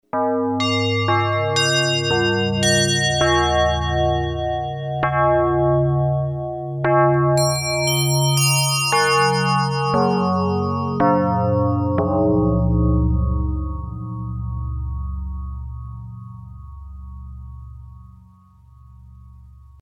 demo bass + portamento